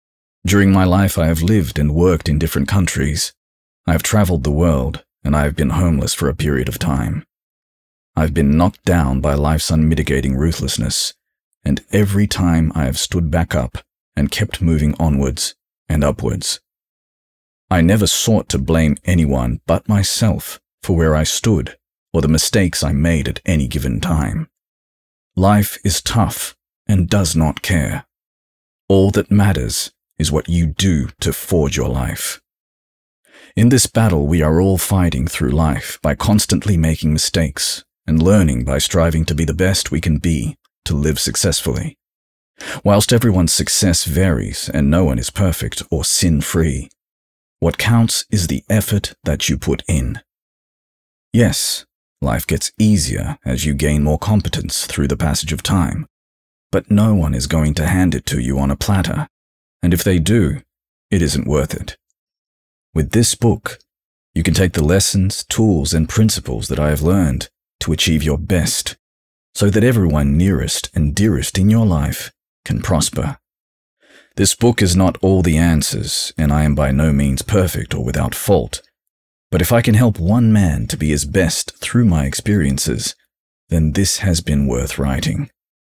Male
Audiobooks
Words that describe my voice are Deep, Tenor, Credible.
All our voice actors have professional broadcast quality recording studios.